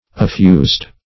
& p. p. Affused (-f[=u]zd"); p. pr.